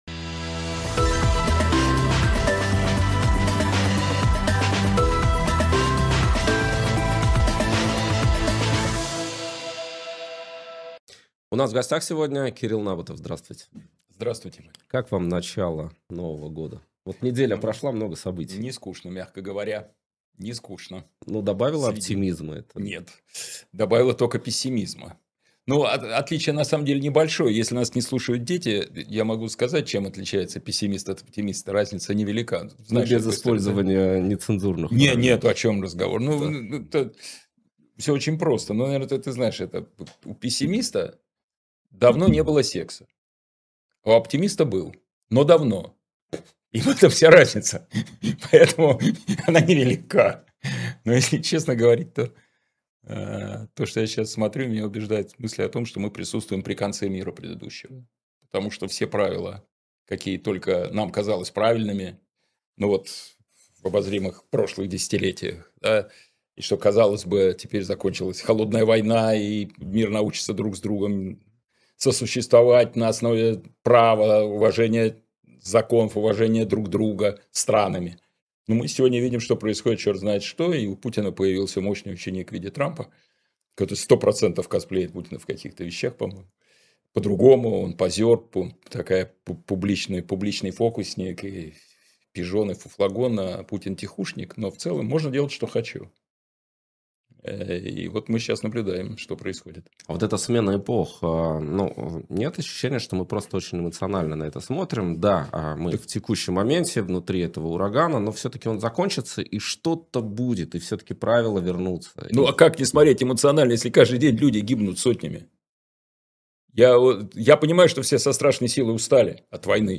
Кирилл Набутов журналист, телеведущий